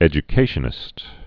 (ĕjə-kāshə-nĭst) also ed·u·ca·tion·al·ist (-shə-nə-lĭst)